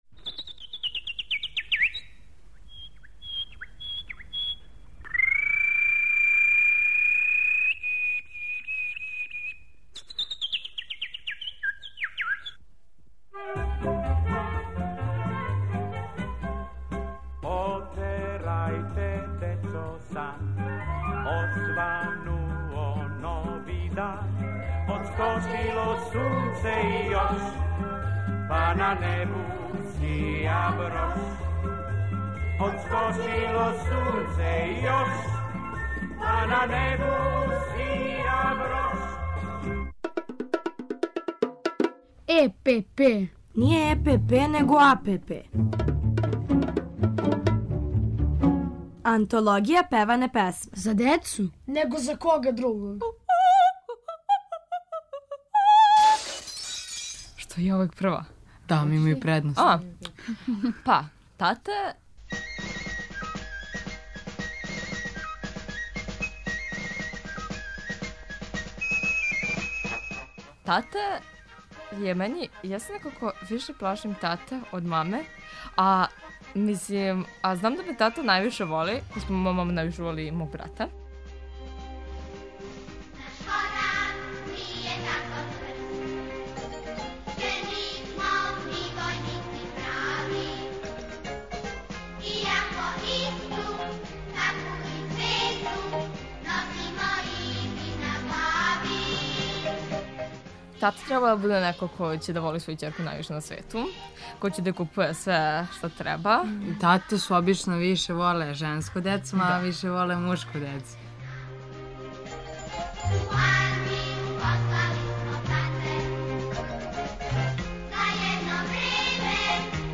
У серијалу Антологија певане песме певамо и причамо о татама, уз Колибри и Дечију драмску групу Радио Београда.